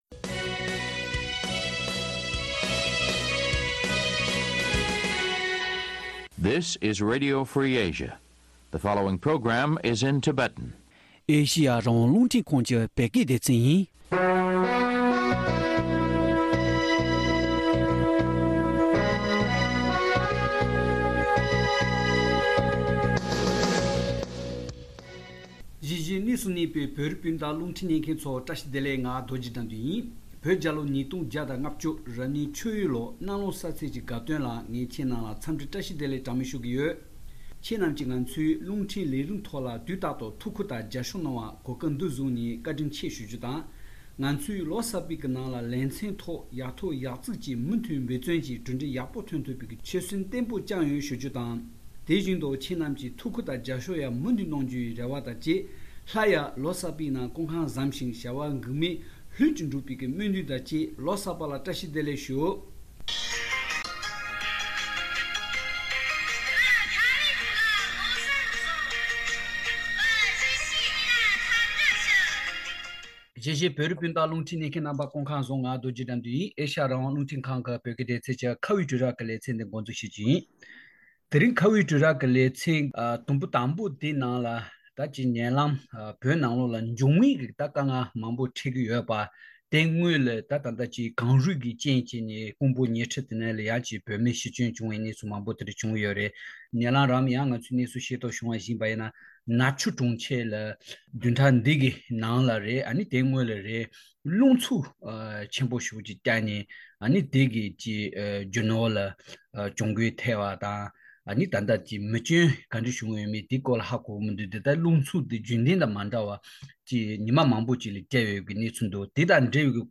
བཅའ་འདྲི་བྱེད་པའི་ལེ་ཚན་དུམ་བུ་དང་པོ